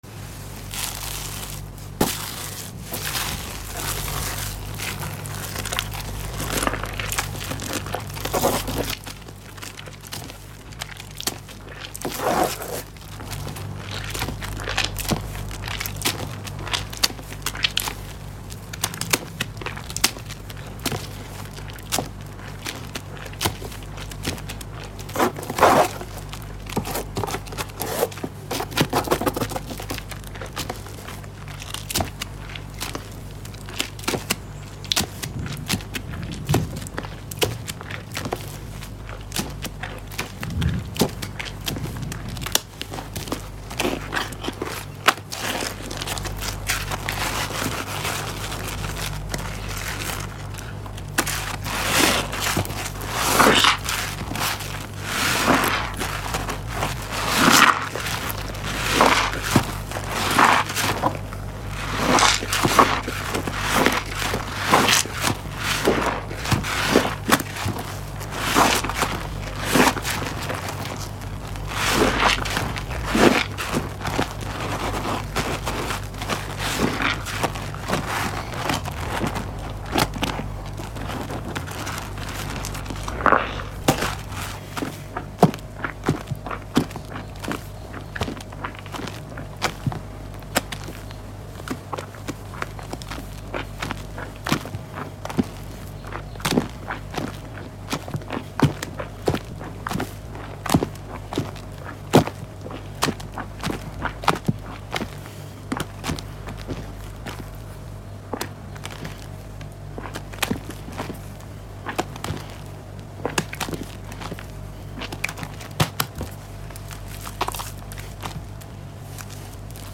ASMR PLAY WITH SAVED PRODUCT💜🩷🧡